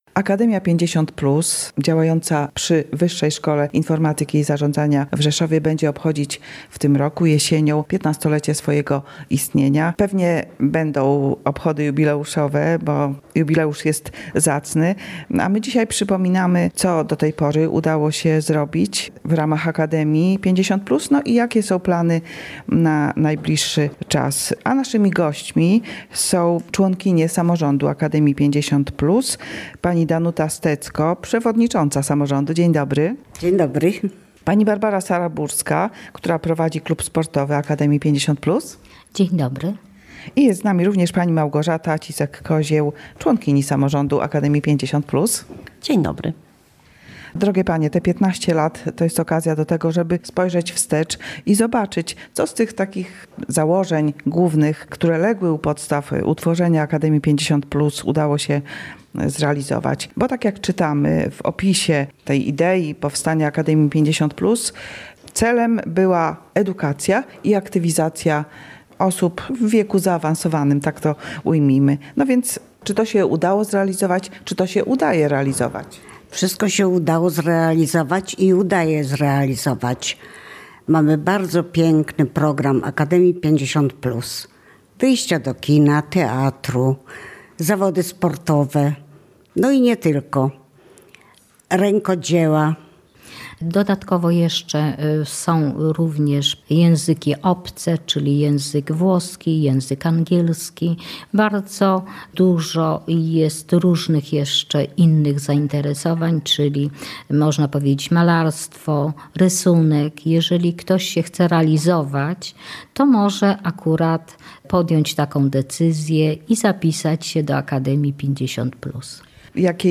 Przedstawicielki Samorządu Akademii 50+ opowiedziały w audycji "Kiedy nadchodzi jesień" o zajęciach dla seniorów i planach na najbliższe miesiące.